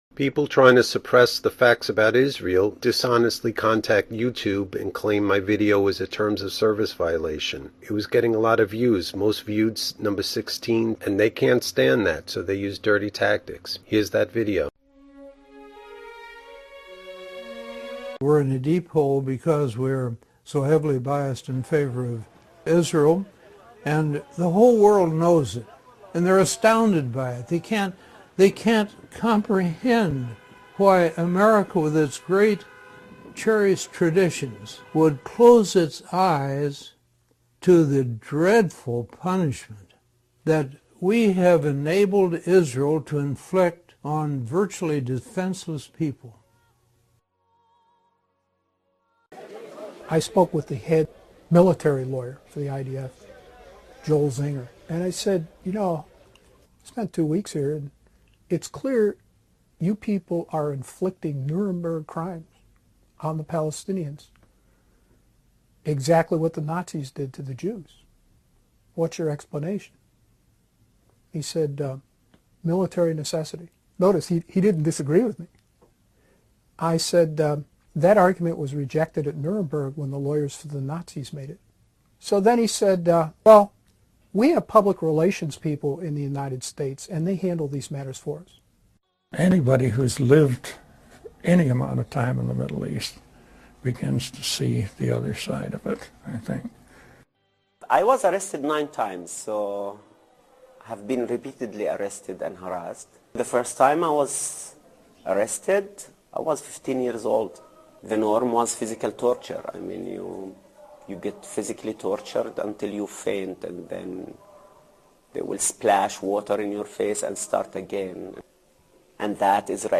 The testimonies presented in this video come from credible voices, including former US diplomats and military legal experts, who paint a devastating picture of American complicity in the suffering of the Palestinian people.
The voices in this video include those who have witnessed the reality on the ground: